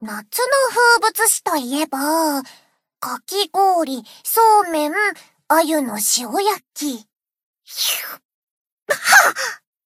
贡献 ） 分类:蔚蓝档案语音 协议:Copyright 您不可以覆盖此文件。
BA_V_Izumi_Swimsuit_Cafe_Monolog_1.ogg